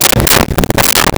Vehicle Door Open Close
Vehicle Door Open Close.wav